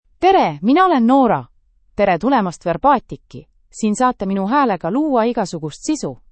Nora — Female Estonian (Estonia) AI Voice | TTS, Voice Cloning & Video | Verbatik AI
Nora is a female AI voice for Estonian (Estonia).
Voice sample
Female
Nora delivers clear pronunciation with authentic Estonia Estonian intonation, making your content sound professionally produced.